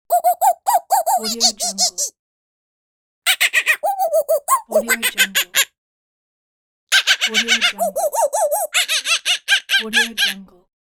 Cartoon Monkey Talk Bouton sonore